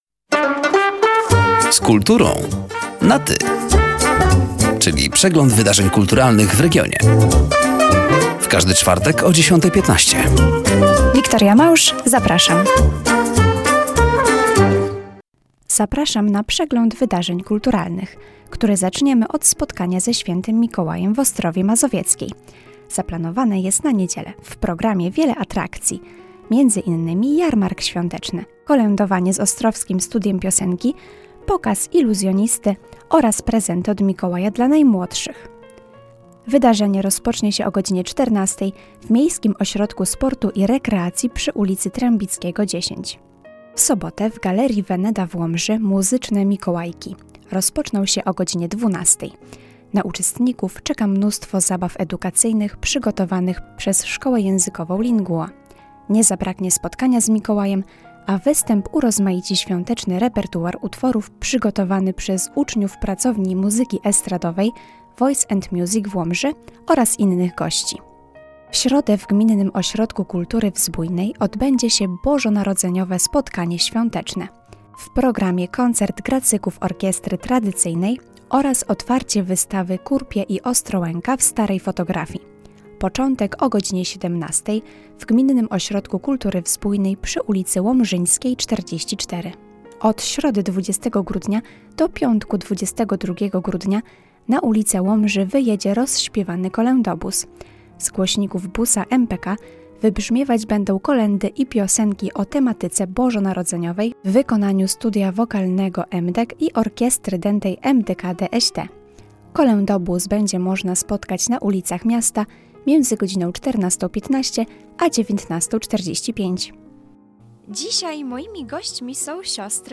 Zapraszamy do zapoznania się ze zbliżającymi wydarzeniami kulturalnymi oraz do wysłuchania rozmowy: